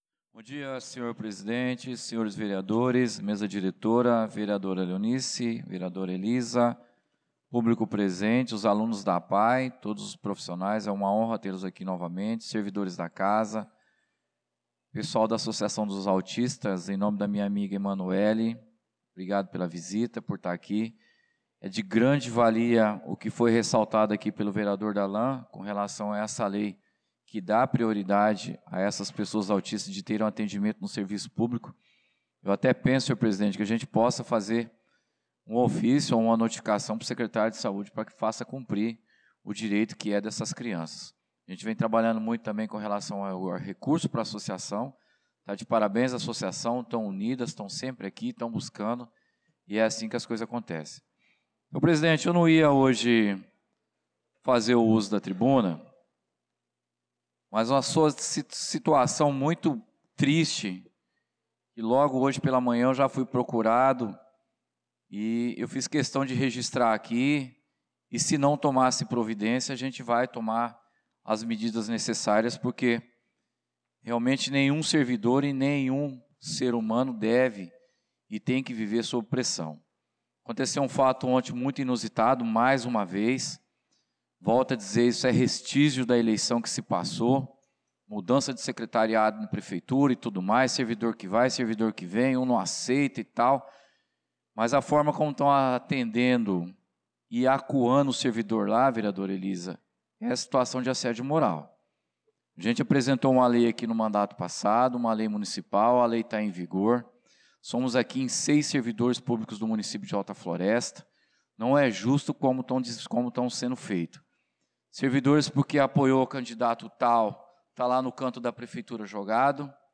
pronunciamento do vereador Claudinei de Jesus na Sessão Ordinária do dia 01/04/2025